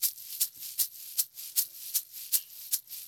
ICE SHAKER.wav